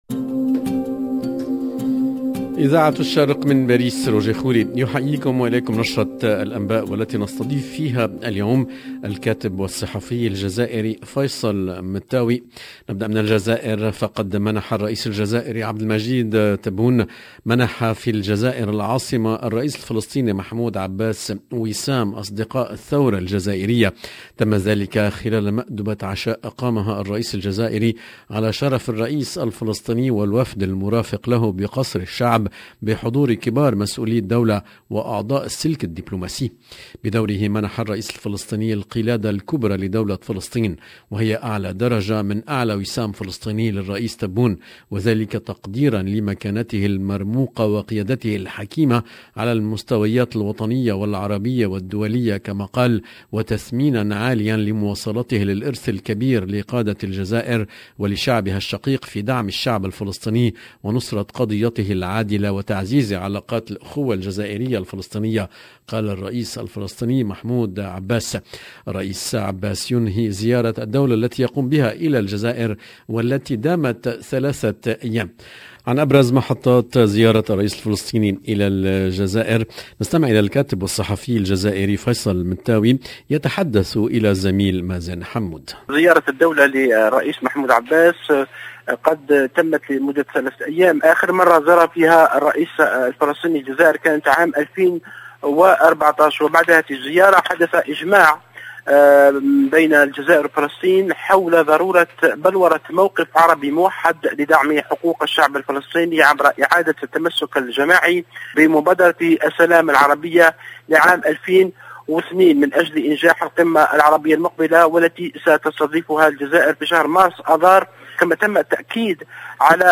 LE JOURNAL DU SOIR EN LANGUE ARABE DU 7/12/21